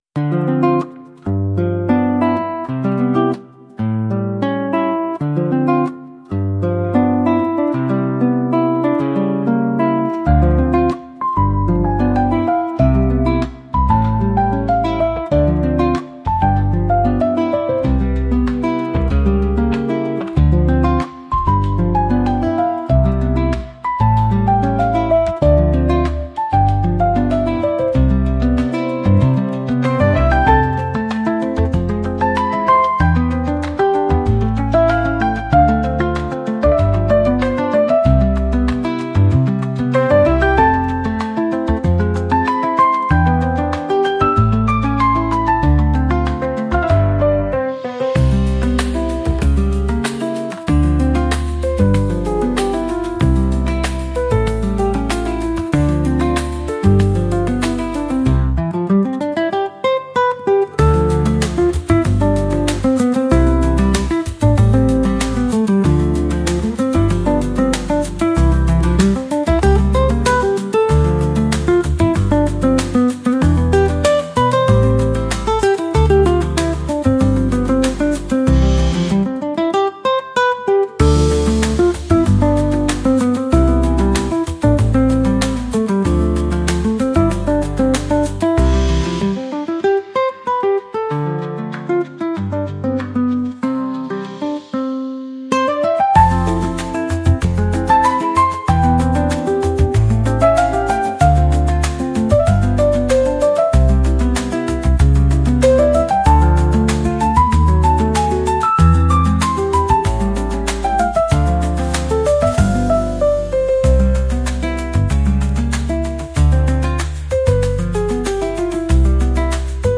100 BPM 3:04
Acoustic
Happy, Laid Back
100 BPM